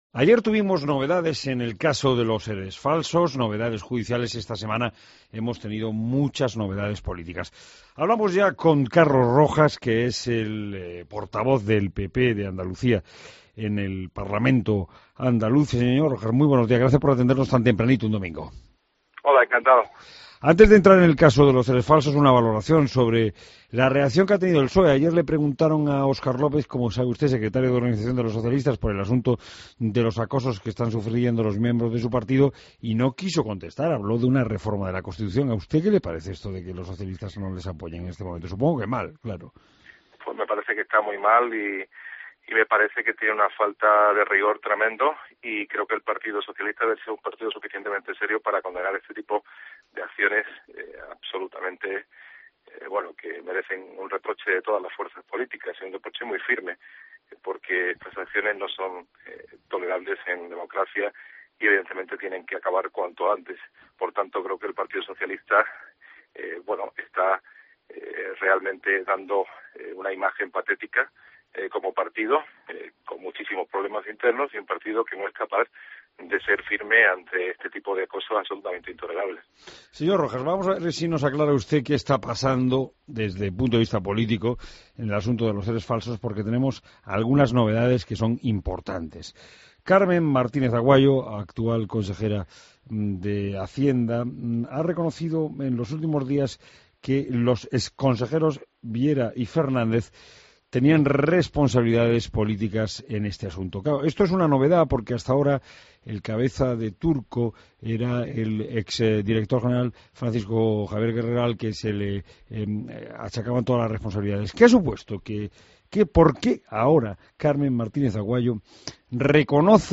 AUDIO: Escucha la entrevista a Carlos Rojas, portavoz del PP en el Parlamento andaluz